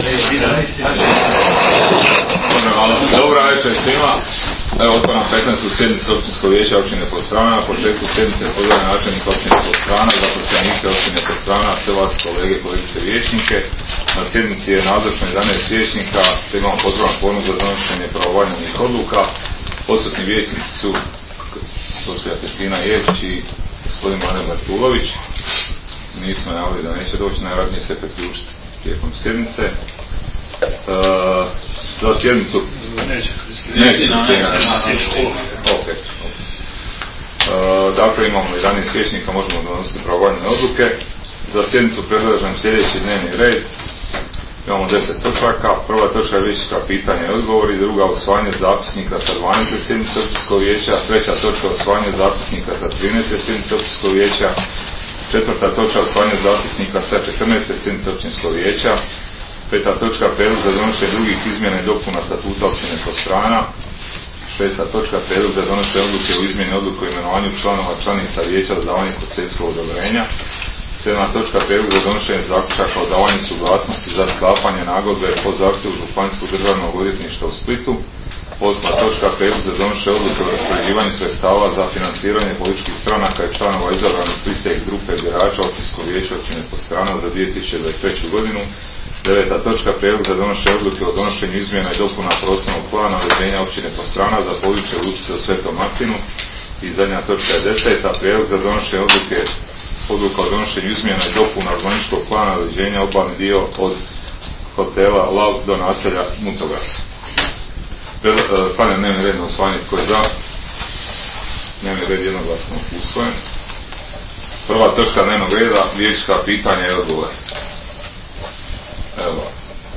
Sjednica će se održati dana 27. veljače (ponedjeljak) 2023. godine u 19,00 sati u vijećnici Općine Podstrana.